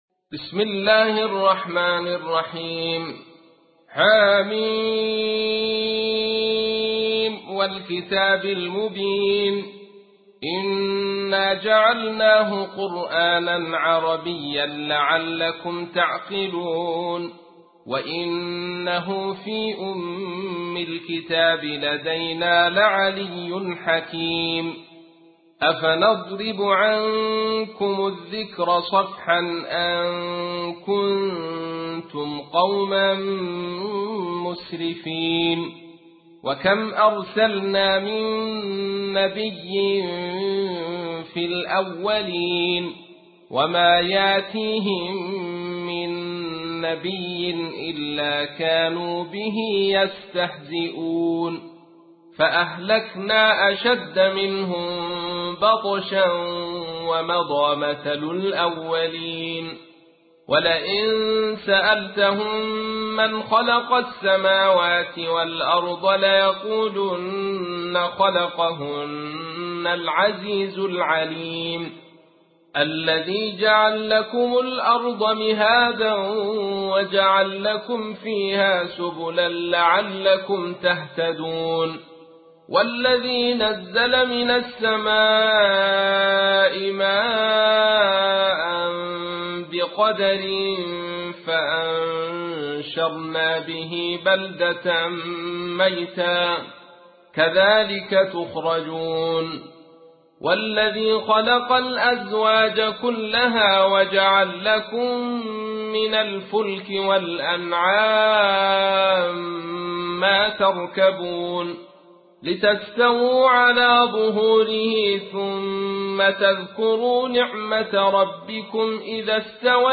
تحميل : 43. سورة الزخرف / القارئ عبد الرشيد صوفي / القرآن الكريم / موقع يا حسين